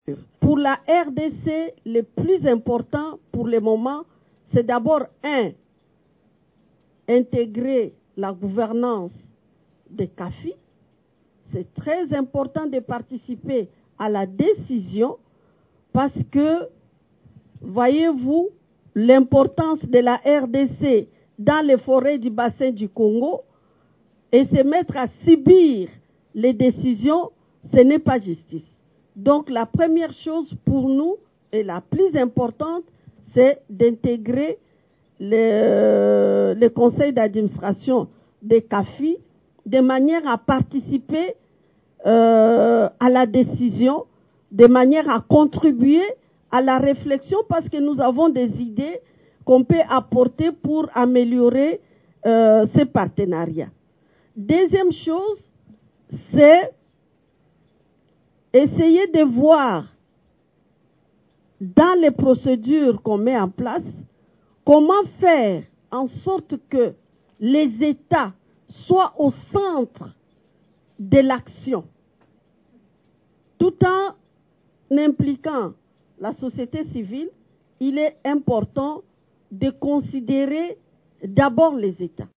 L’Initiative pour la Forêt de l'Afrique centrale (CAFI) a célébré son dixième anniversaire à New York (USA) au cours du 80eme Assemblée générale des Nations unies, le 24 septembre dernier marquant une décennie d'efforts concertés pour la protection des forêts du Bassin du Congo et le soutien de ses populations locales et autochtones. À cette occasion, la Professeure Marie Nyange Ndambo, ministre de l’Environnement, développement durable et nouvelle économie du Climat, a salué les réalisations du partenariat et plaidé pour des réformes audacieuses afin d'amplifier les résultats futurs du Fonds CAFI.